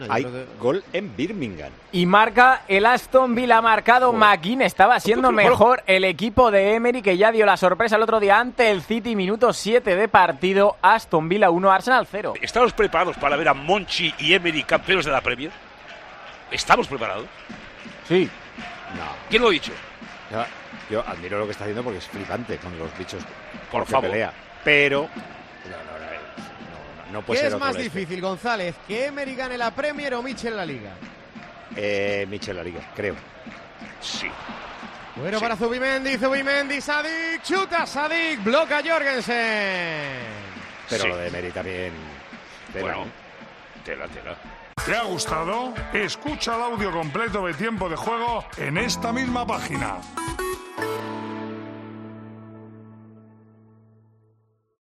Dos de los equipos más en forma de esta temporada son analizados en Tiempo de Juego por Paco González.